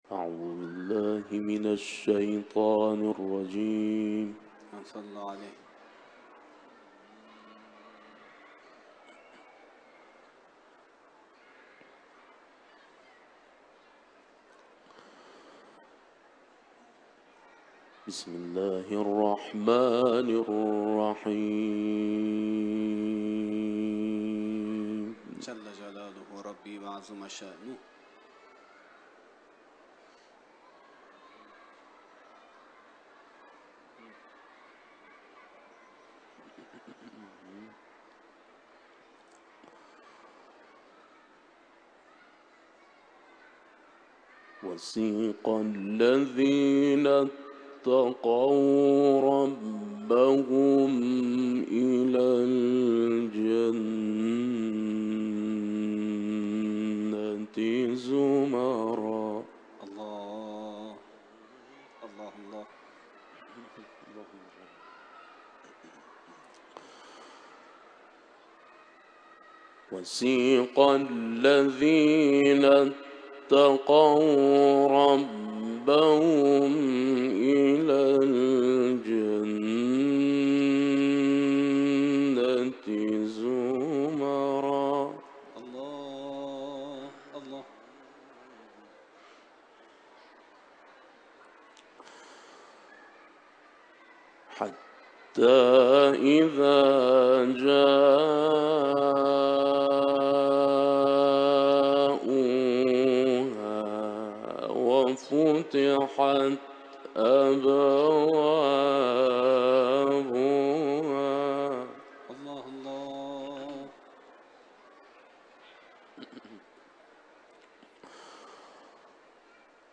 حرم مطهر رضوی میں کی گیی تلاوت ایکنا پیش کررہی ہے۔